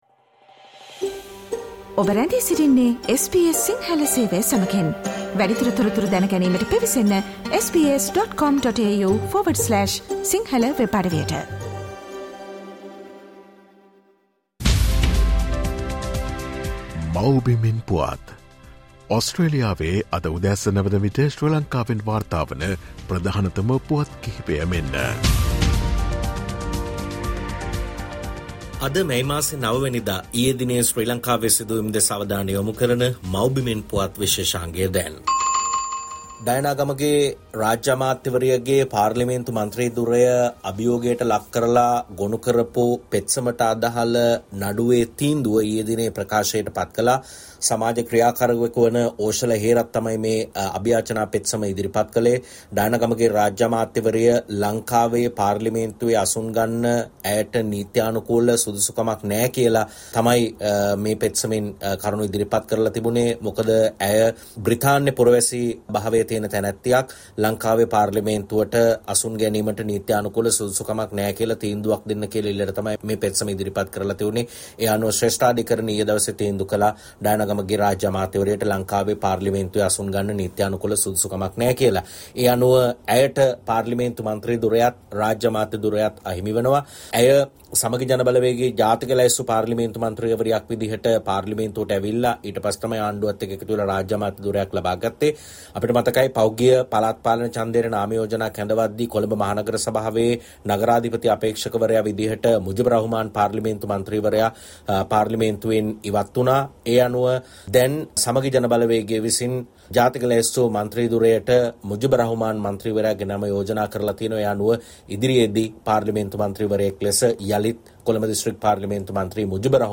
The latest news reported from Sri Lanka as of this morning in Australia time from the “Homeland News” feature